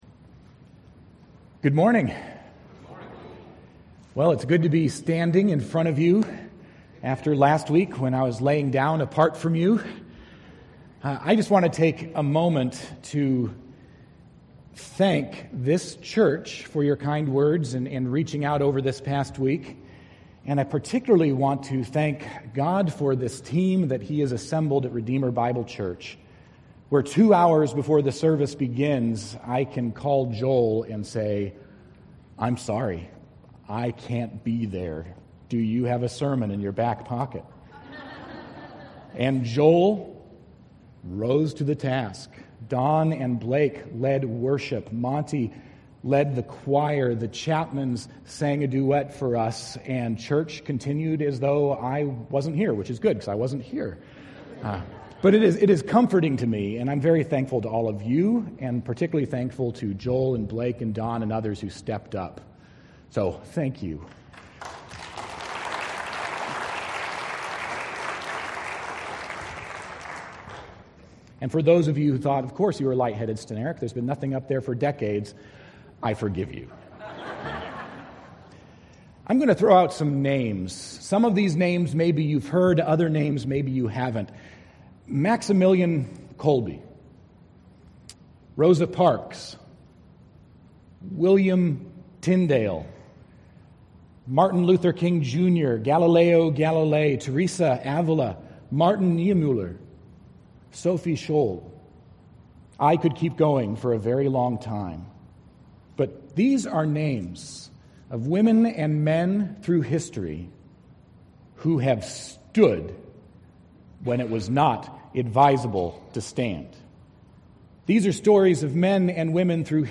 Will You Stand Despite Risk? - Redeemer Bible Church Dallas TX
Sermon9.23.18.mp3